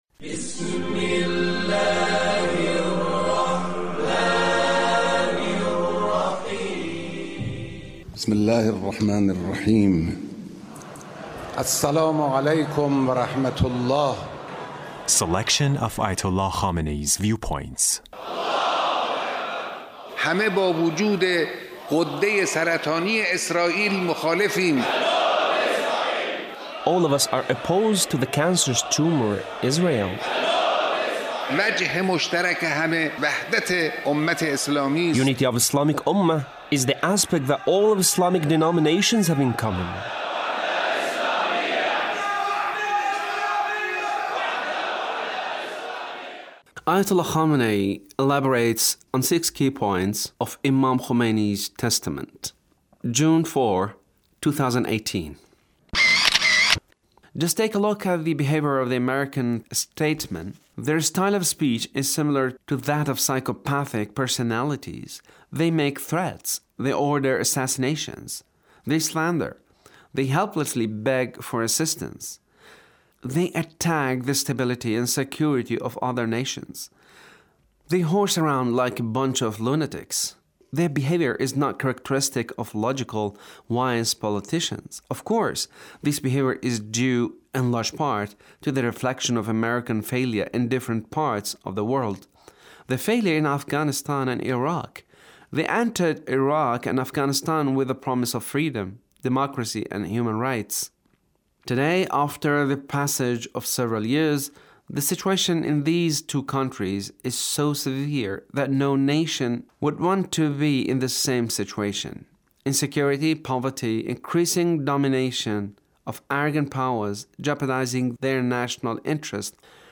Leader's speech (1423)